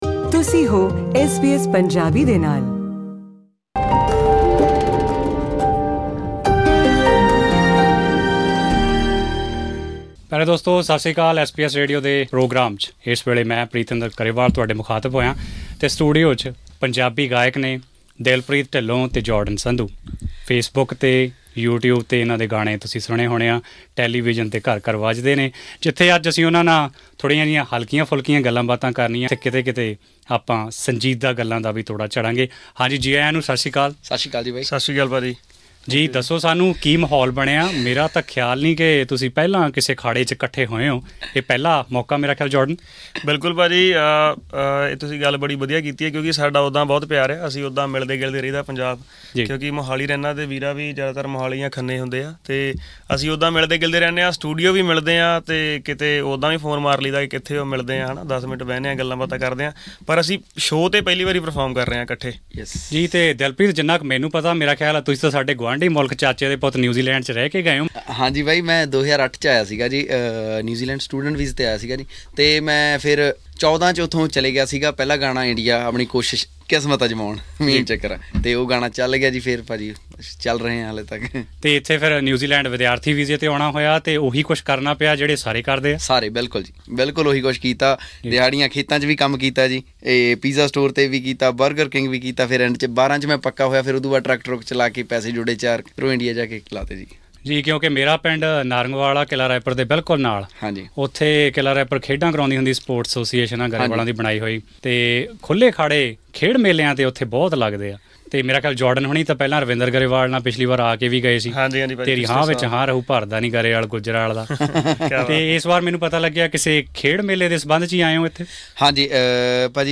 Well-known Punjabi singers Dilpreet Dhillon and Jordan Sandhu joined us in the Melbourne studio to speak about their singing career and the role of social media in the Punjabi music industry.